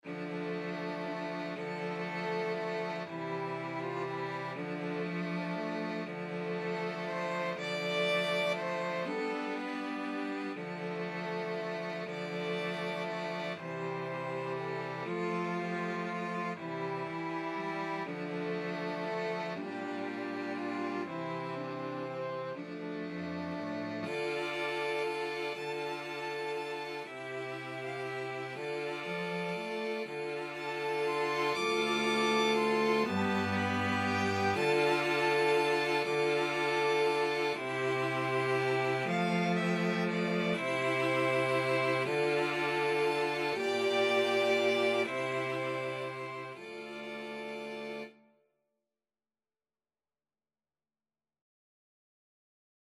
Free Sheet music for 2-Violins-2-Cellos
Violin 1Violin 2Cello 1Cello 2
D minor (Sounding Pitch) (View more D minor Music for 2-Violins-2-Cellos )
Lento =120
3/4 (View more 3/4 Music)
Traditional (View more Traditional 2-Violins-2-Cellos Music)